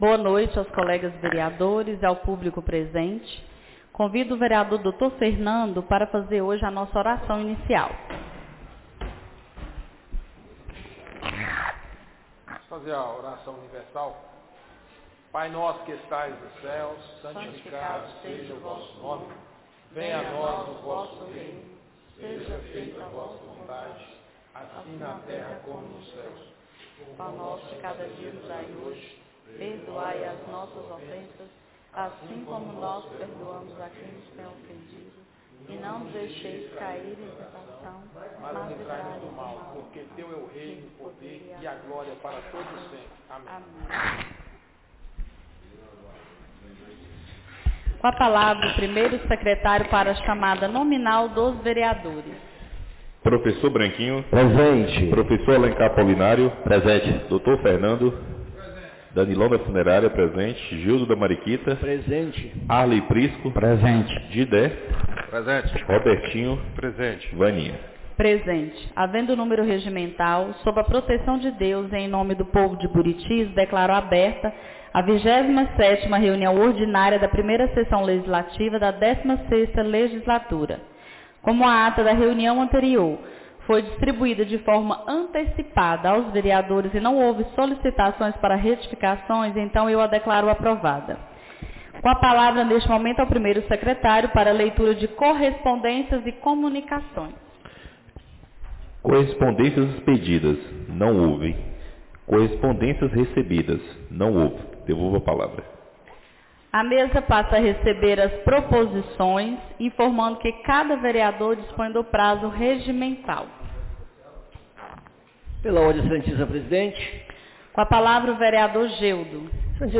27ª Reunião Ordinária da 1ª Sessão Legislativa da 16ª Legislatura - 18-08-25 — Câmara Municipal de Buritis - MG